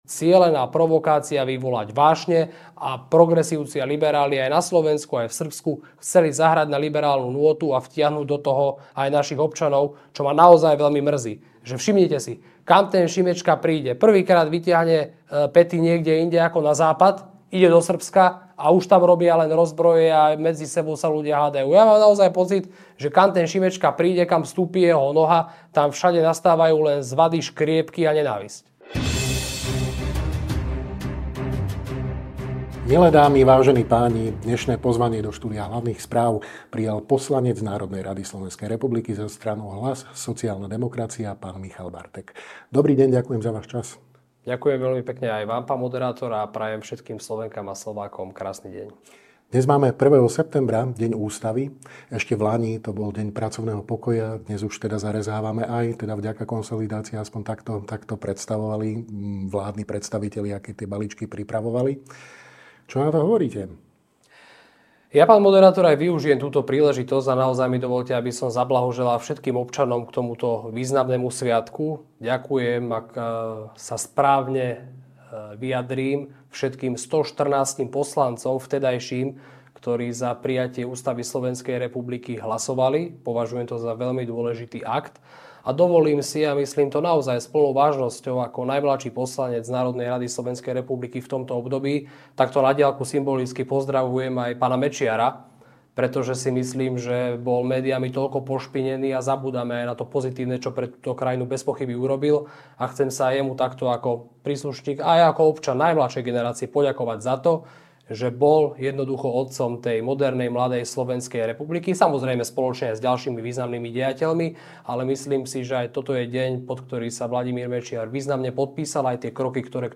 Viac sa dozviete vo videorozhovore s poslancom NR SR za Hlas-SD, Michalom Bartekom.